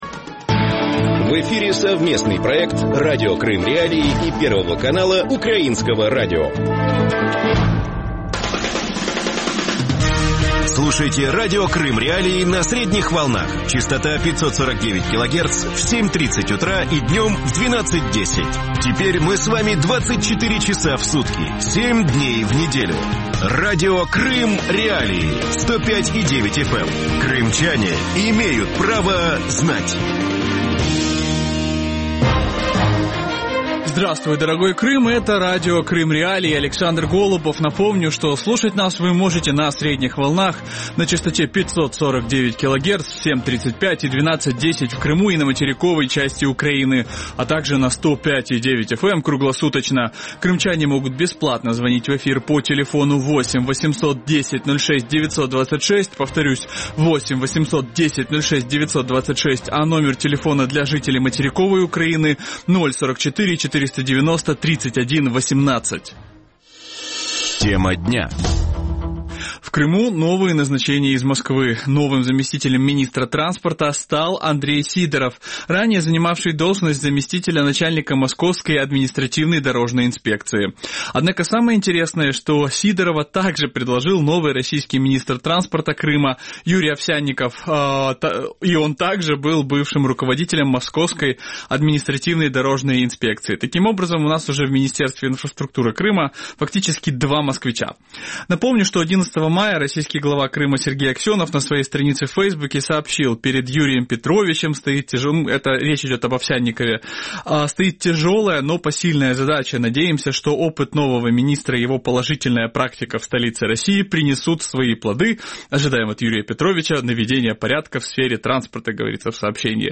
крымский политолог
украинский политолог.